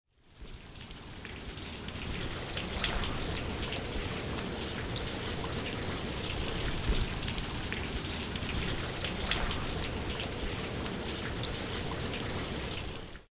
Yogstation / sound / ambience / acidrain_start.ogg
acidrain_start.ogg